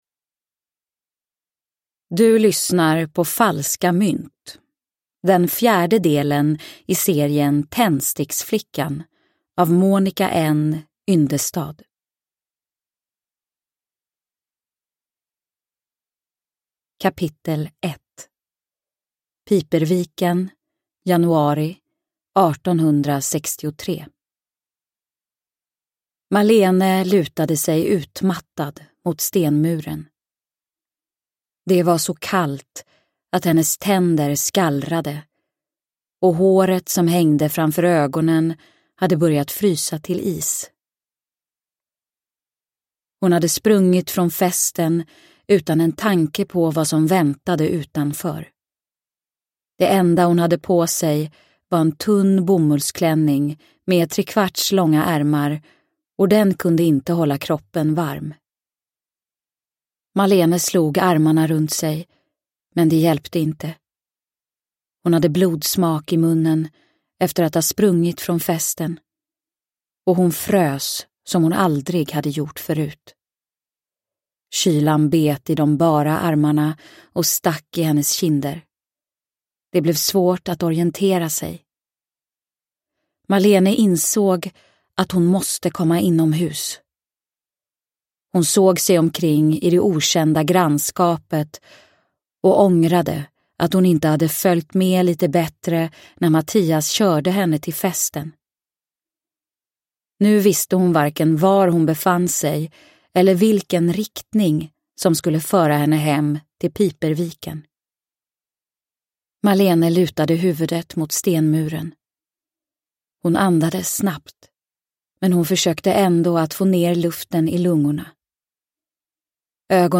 Falska mynt – Ljudbok – Laddas ner